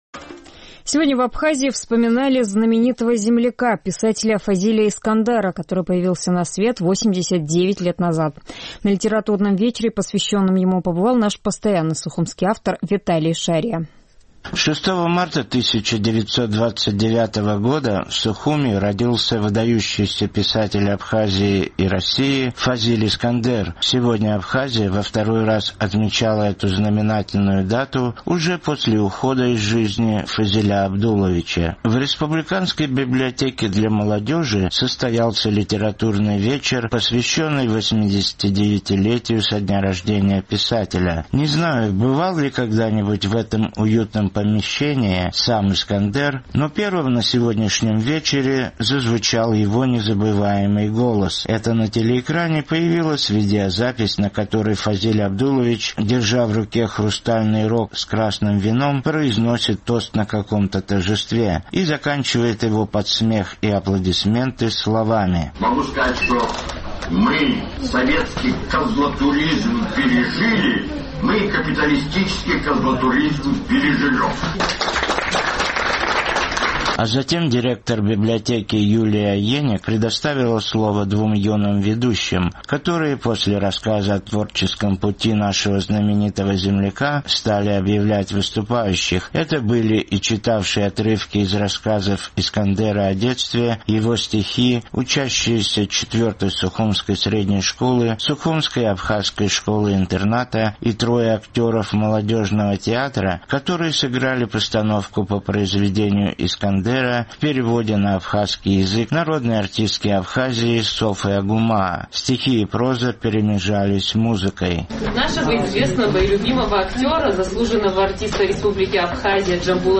В республиканской библиотеке для молодежи состоялся литературный вечер, посвященный 89-летию со дня рождения писателя. На вечере царила очень камерная и теплая обстановка.
Впрочем, как бы то ни было, но первым на сегодняшнем вечере зазвучал незабываемый голос Фазиля Искандера.
И закачивает его под смех и аплодисменты словами:
Стихи и проза перемежались музыкой: